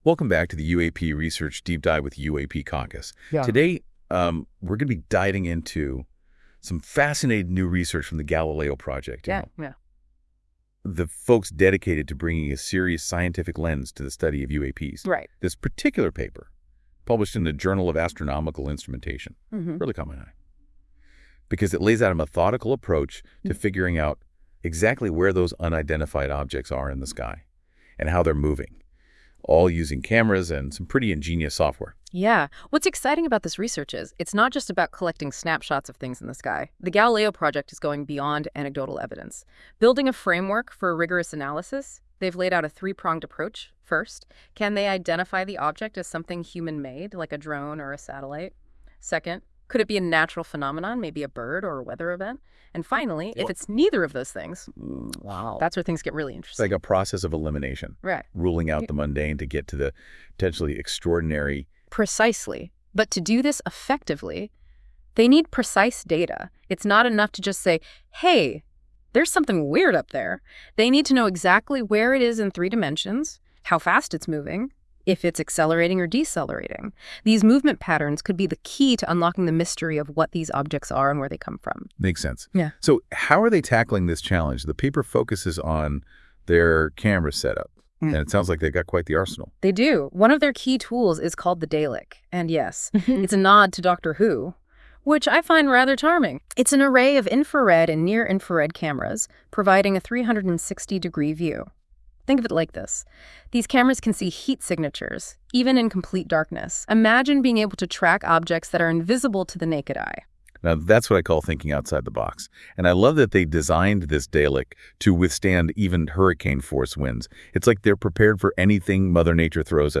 This AI-generated audio may not fully capture the research's complexity.
Audio Summary